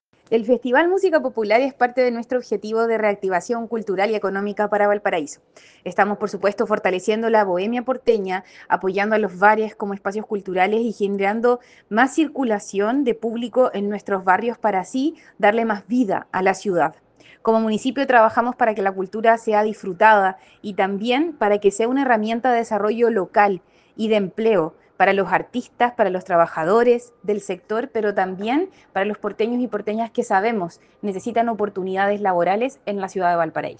Alcaldesa-Camila-Nieto-Hernandez.mp3